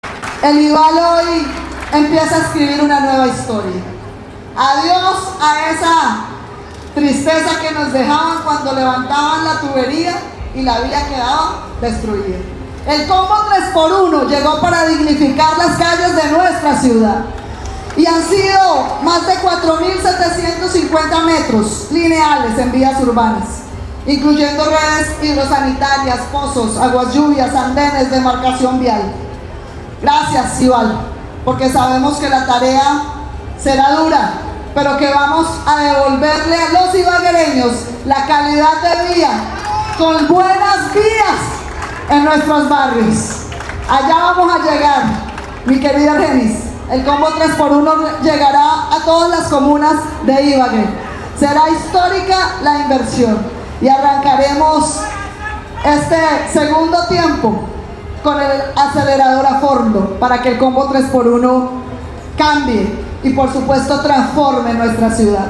En su discurso la alcaldesa Johana Aranda entregó un balance de lo que ha sido su administración en estos primeros seis meses de 2025, resaltando las acciones de la Empresa Ibaguereña de Acueducto y Alcantarillado, IBAL.
Rendicion-de-cuentas.-Johana-Aranda.mp3